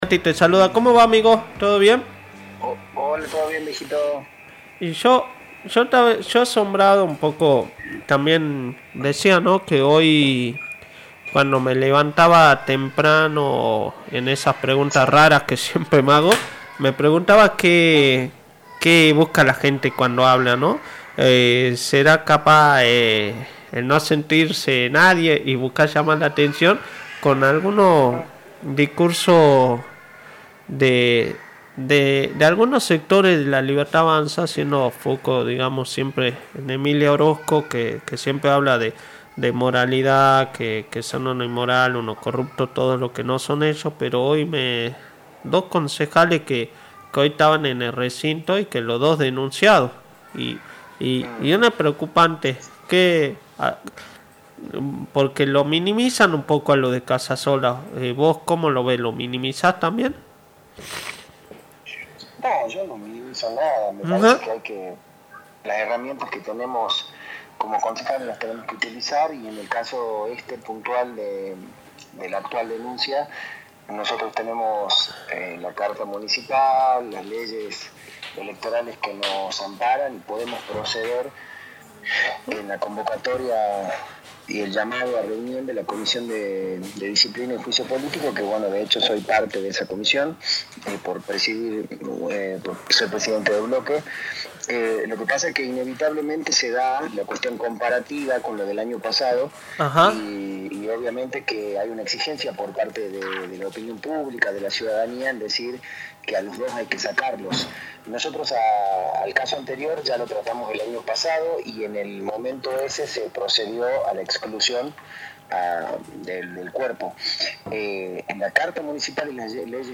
Crisis de representatividad Durante la entrevista, el concejal fue crítico con el actual escenario político y aseguró que existe una fuerte crisis de representación.